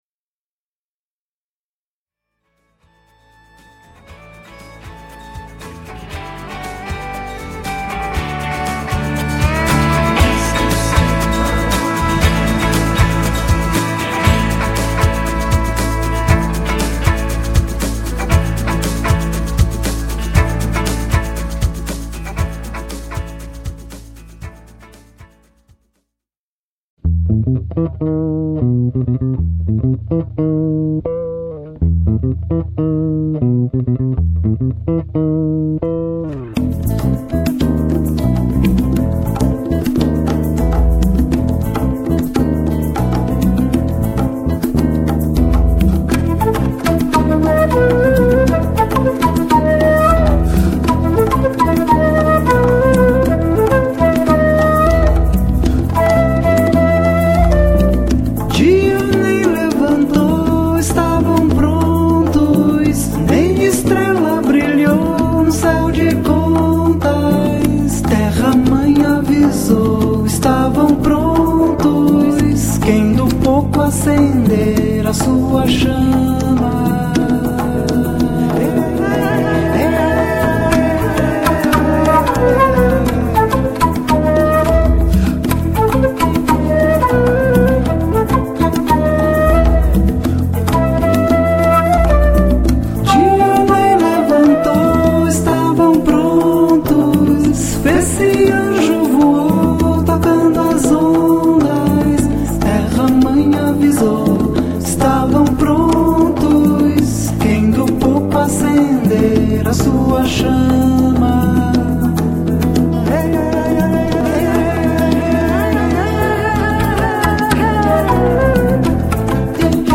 violões
sax e flauta
percussão
baixo